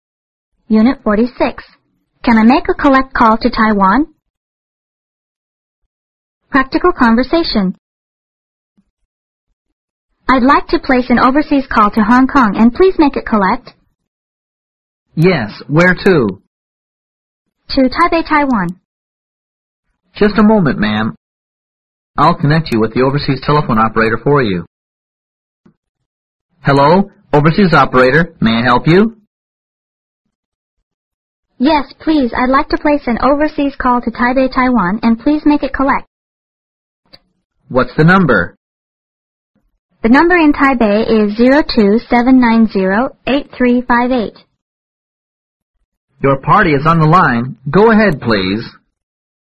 Practical conversation